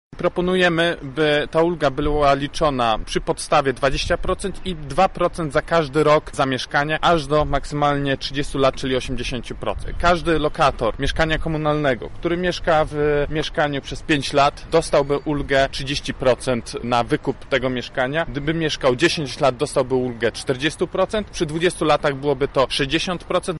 Konferencja Kuleszy odbyła się w miejscu, w którym w maju 2017 roku zawaliła się kamienica przy ul. Lubartowskiej.
Kulesza przedstawił ile wynosiłaby ulga na wykup lokali dla takich osób: